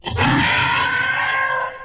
velociraptor.wav